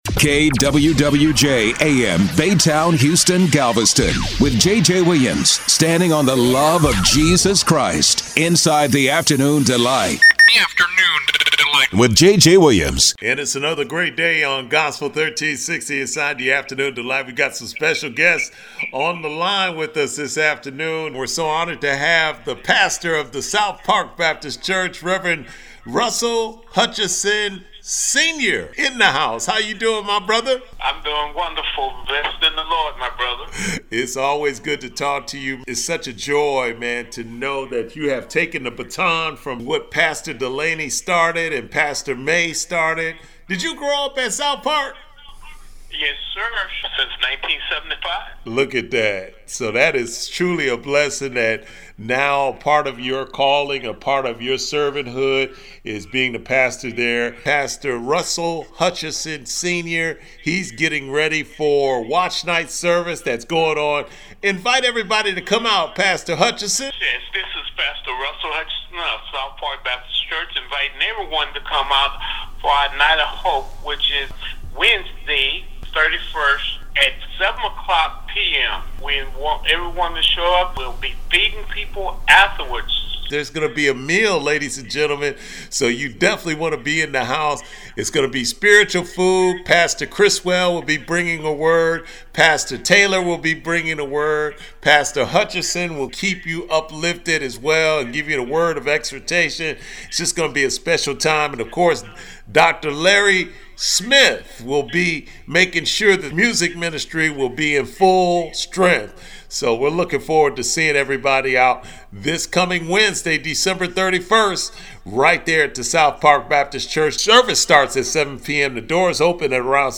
The Interview.mp3